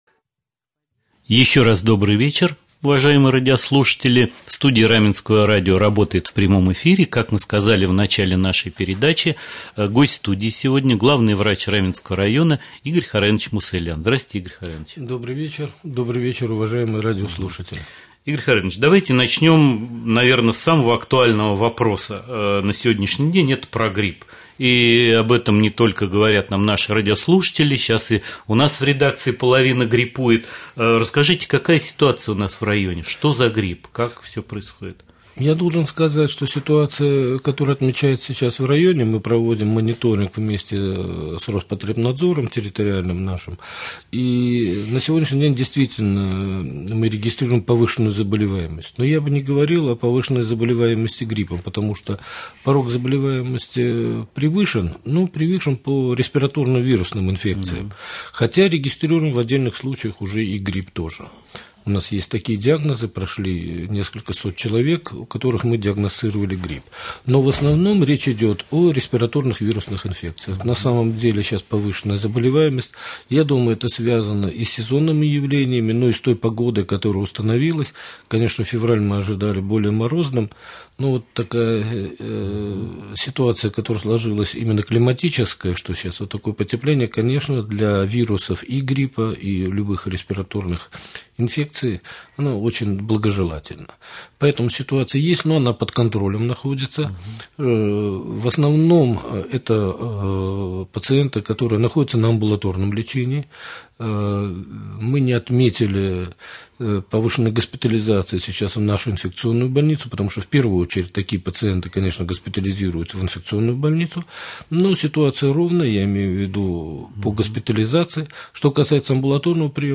3.Прямой-эфир.mp3